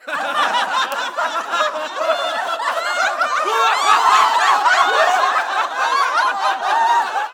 laughter 01
funny group haha happy laugh laughing laughter people sound effect free sound royalty free Funny